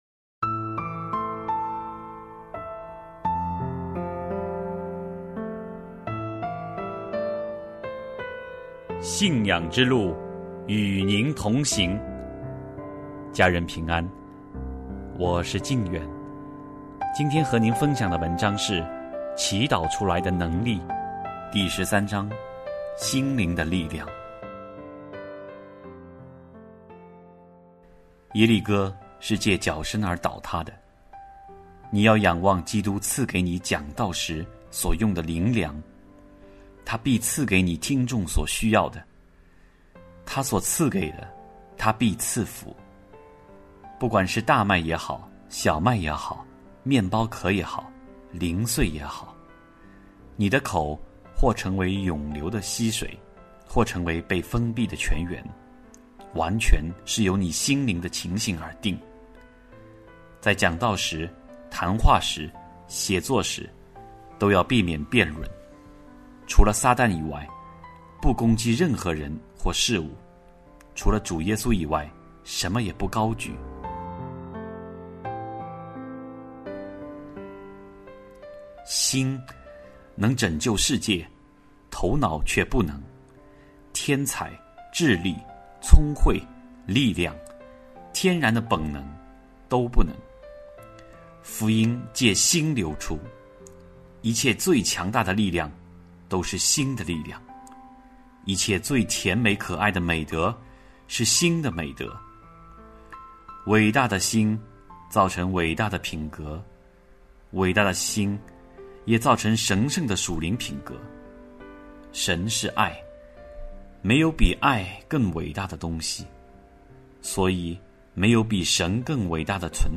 首页 > 有声书 | 灵性生活 | 祈祷出来的能力 > 祈祷出来的能力 第十三章： 心灵的力量